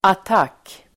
Uttal: [at'ak:]